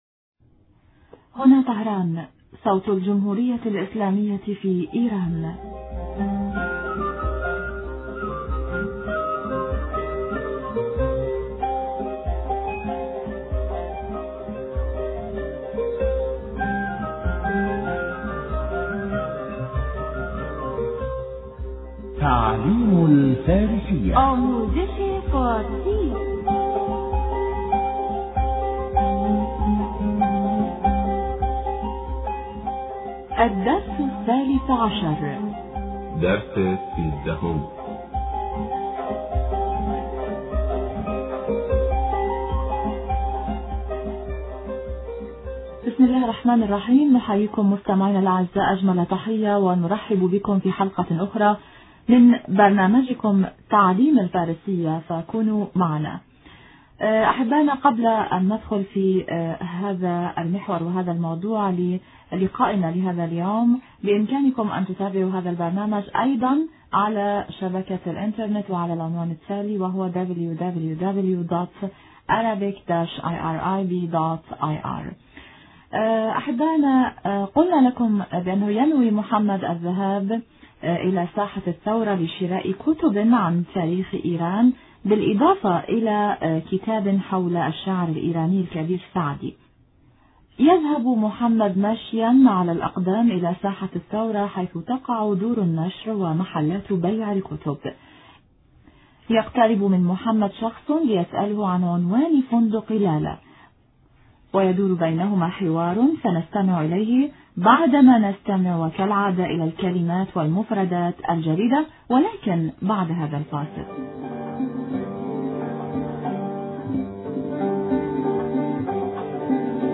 تعليم الفارسية- الدرس 13- في محل بيع الكتب